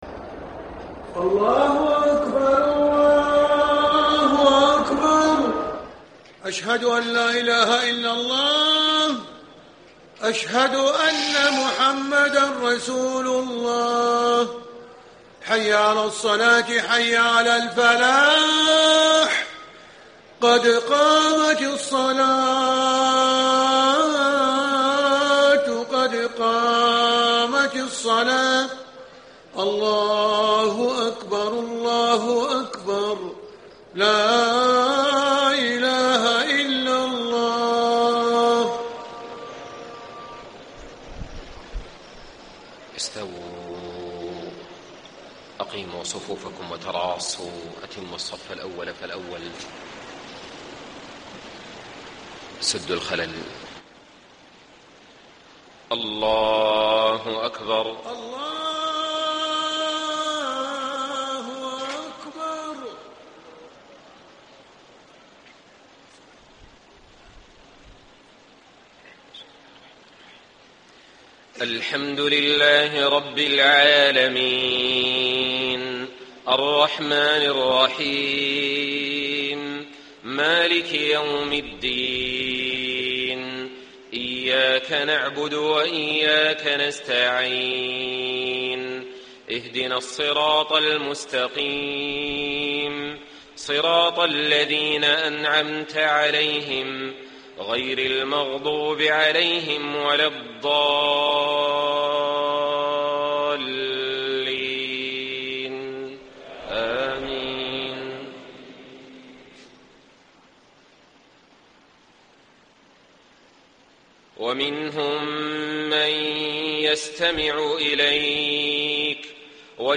صلاة العشاء 8-6- 1435 ما تيسر من سورة الأنعام > 1435 🕋 > الفروض - تلاوات الحرمين